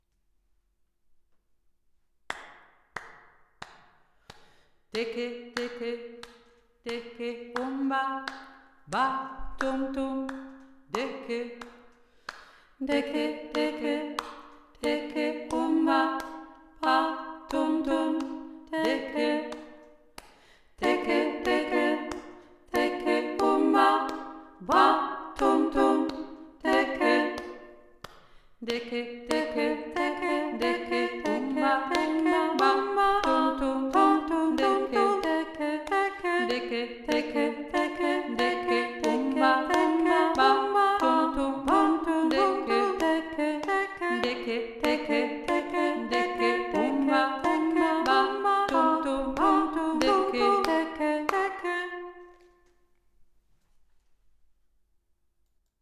3. Stimme